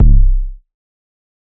808_Oneshot_Short_C
808_Oneshot_Short_C.wav